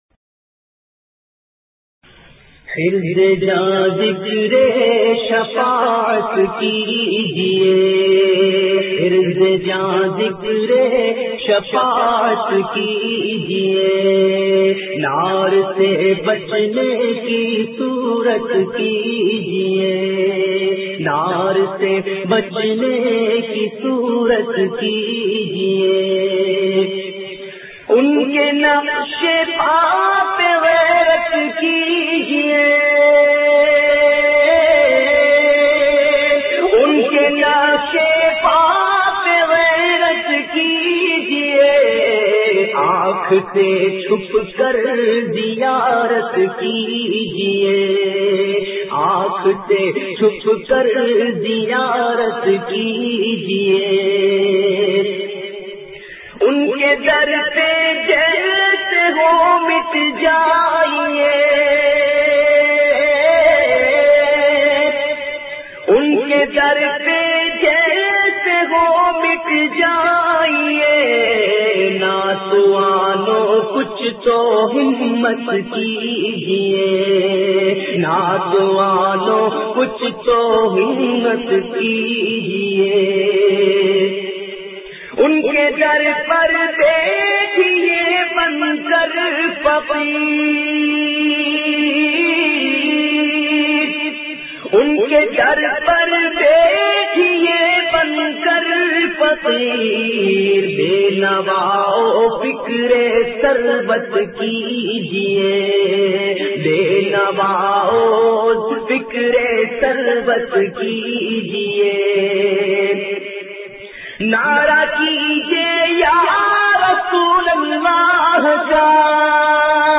Naat Sharif
recited by famous Naat Khawan of Pakistan